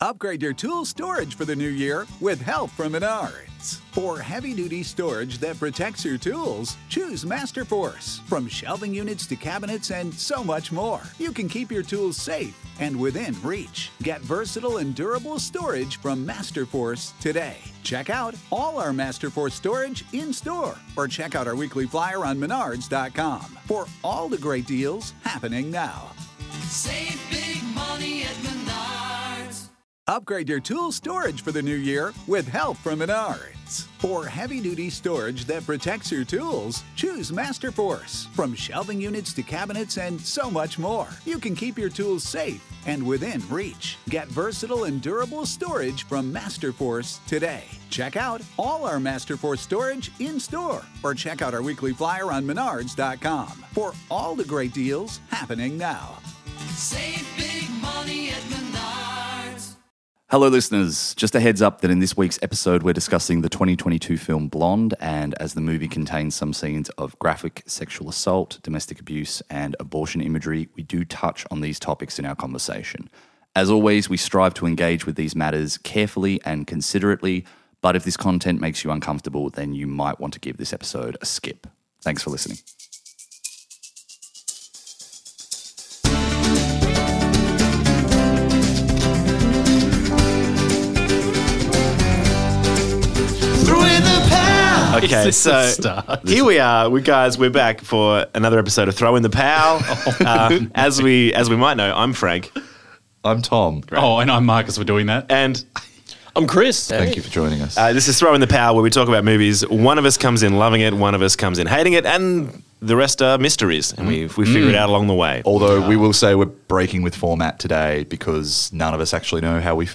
Four Pals and a Blonde walk into a podcast studio, then proceed to have a chinwag over who gentlemen prefer in Andrew Dominick’s 2022 fictionalised rundown of the life of Marilyn (“Pal-rilyn” to her friends) Monroe.